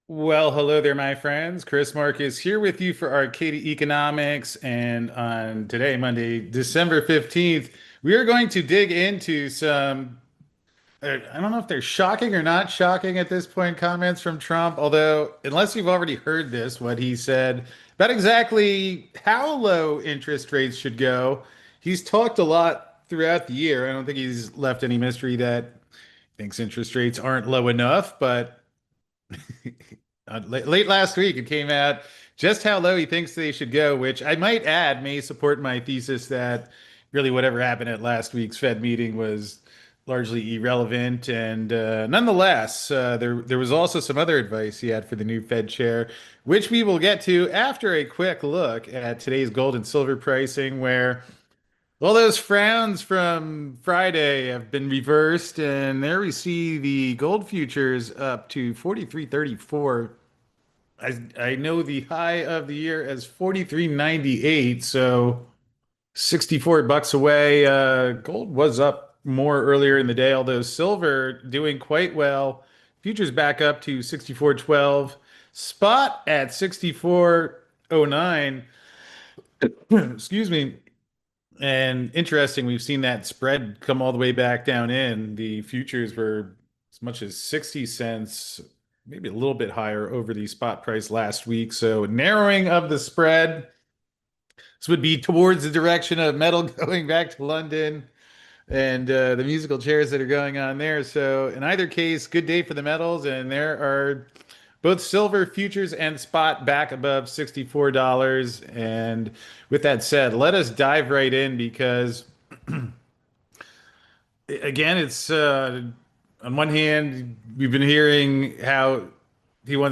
So click to join us for this live call at 5 PM eastern!